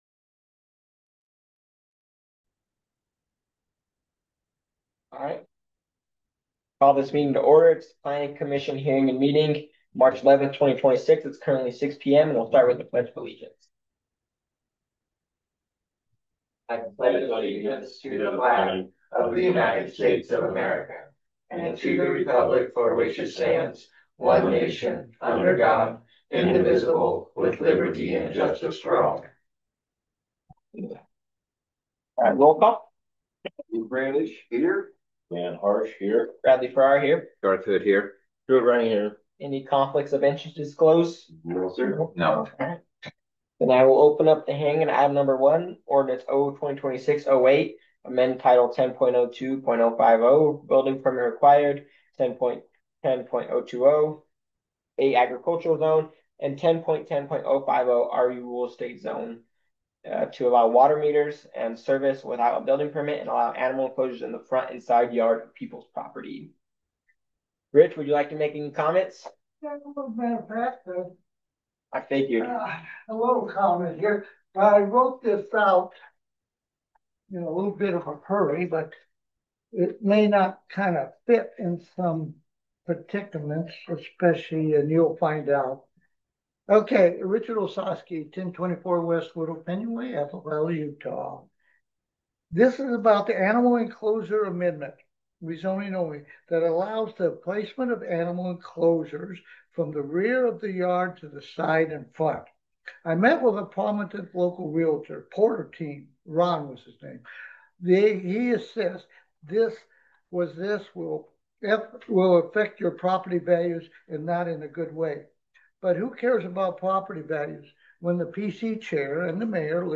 Download 3.11.26 Planning Commission Hearing and Meeting Audio.m4a (opens in new window)